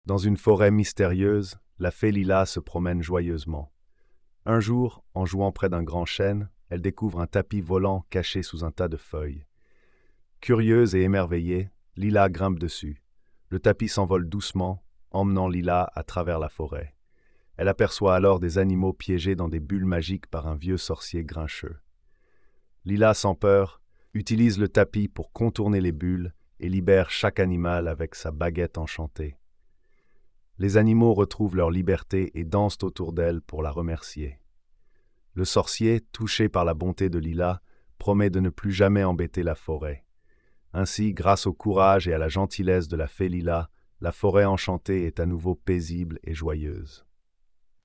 Lila et le Tapis Magique - Conte de fée
🎧 Lecture audio générée par IA